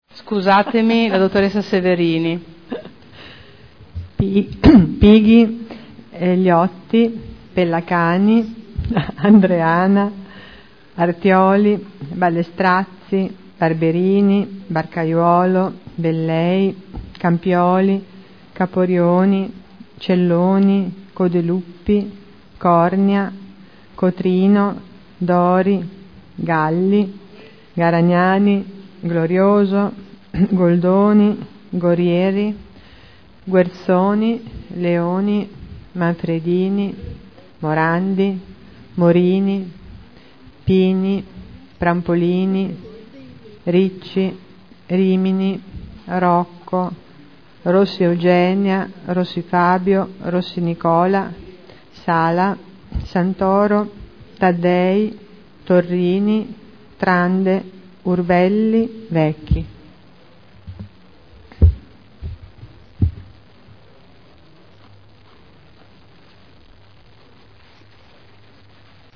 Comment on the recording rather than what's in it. Appello e apertura del Consiglio Comunale